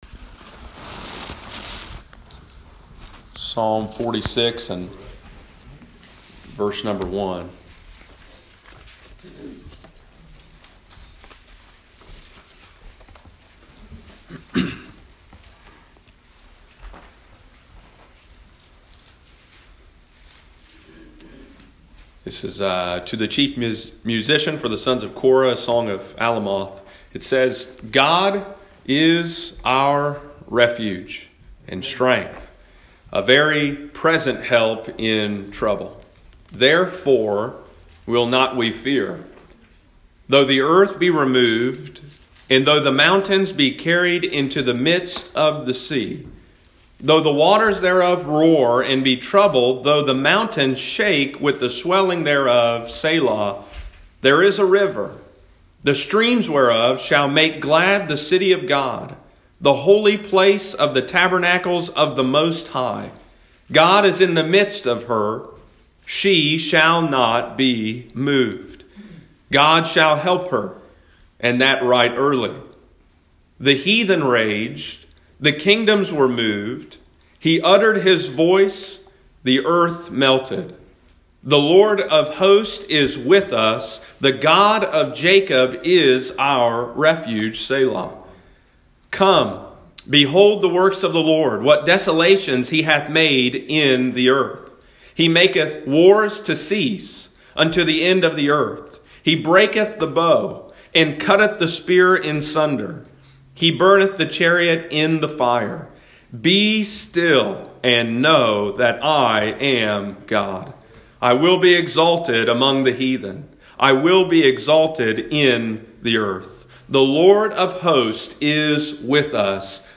Be Still – Lighthouse Baptist Church, Circleville Ohio